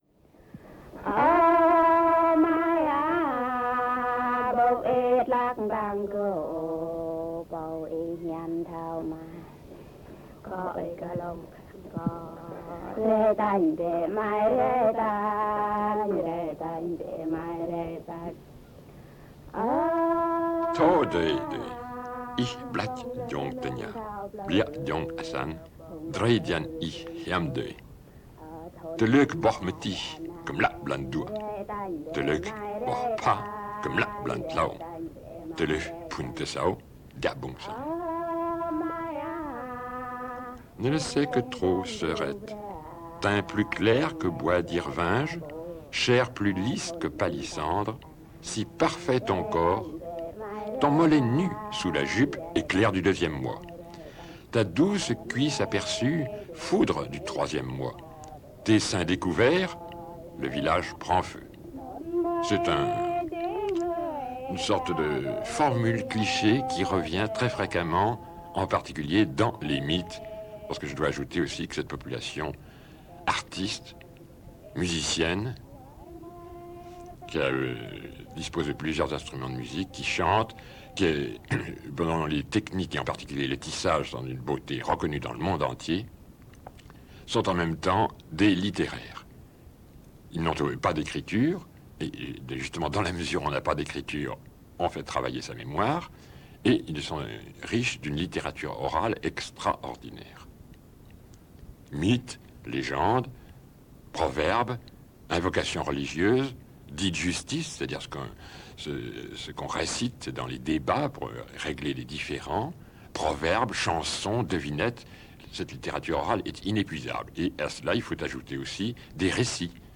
Témoignage oral